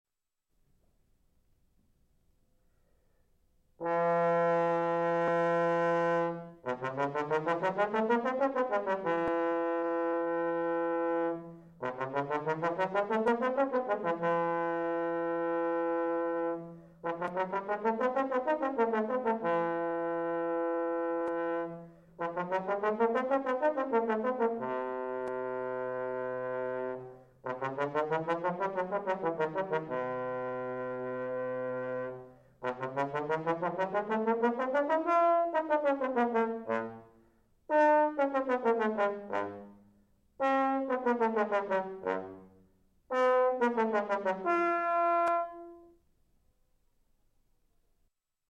Audition Tape (April 1984)
Douglas Yeo, bass trombone.